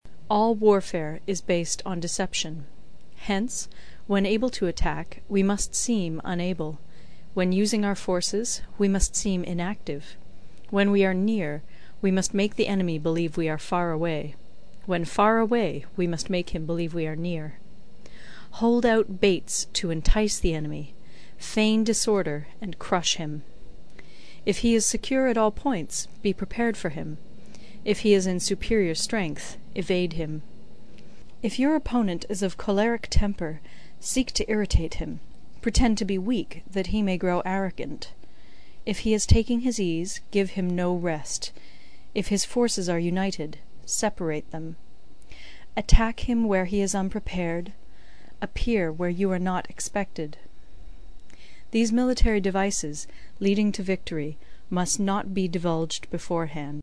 有声读物《孙子兵法》第6期:第一章 始计(6) 听力文件下载—在线英语听力室